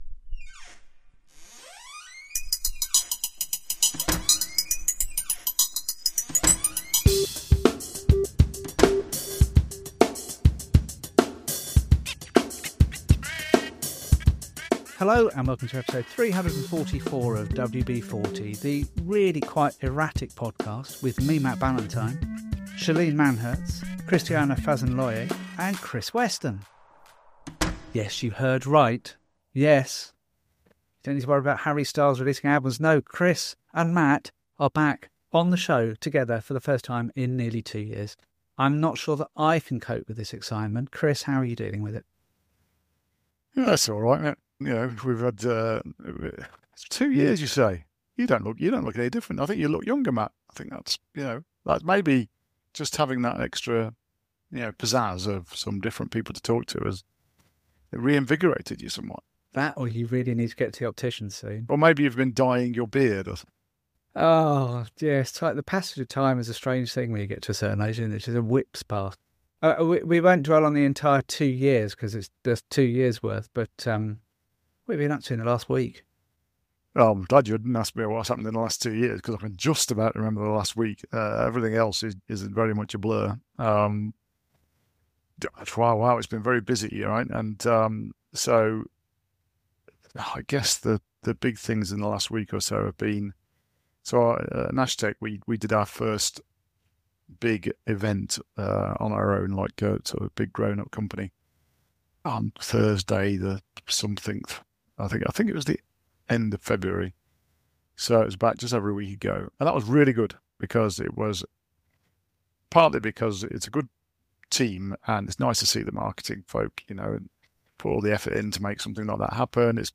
Conversations on how technology is changing how we work with guests most weeks helping us to navigate.